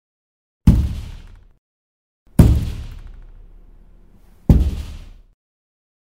Звуки стука в дверь
Стук с большим интервалом